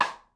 DrRim4.wav